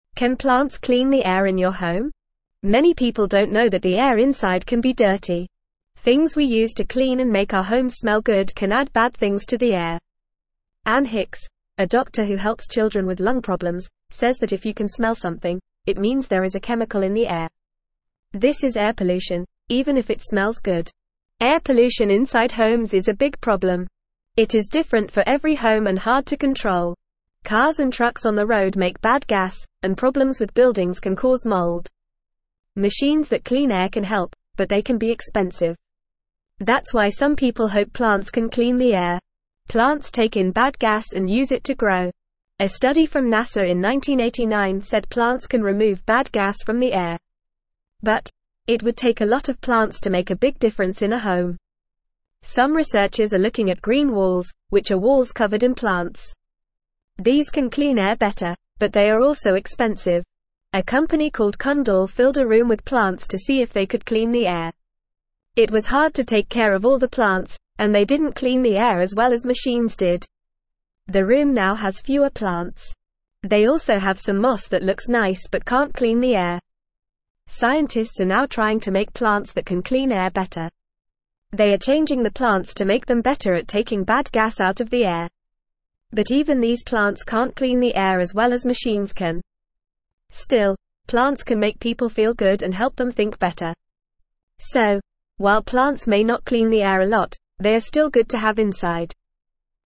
Fast
English-Newsroom-Beginner-FAST-Reading-Can-Plants-Clean-Our-Home-Air.mp3